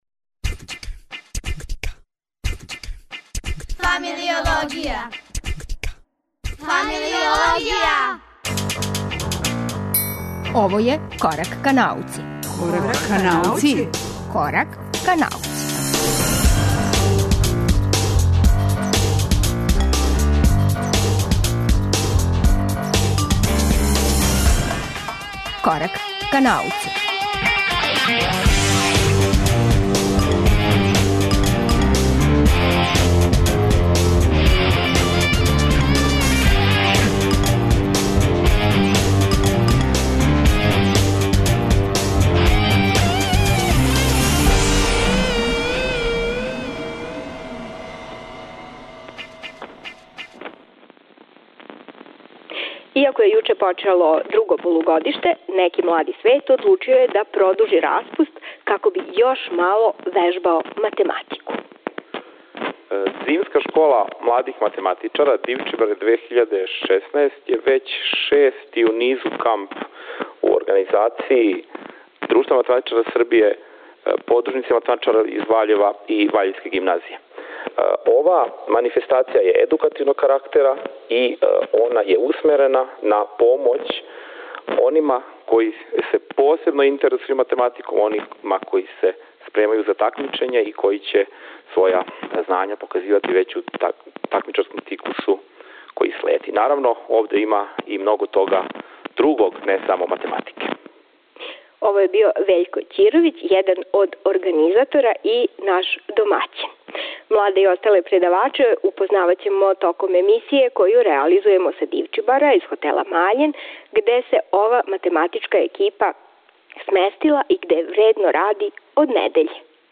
Емисија се емитује са терена на Дивчибарима, уз младе математичаре окупљене у кампу који на Дивчибарима организује Друштво математичара Србије, Подружница из Ваљева.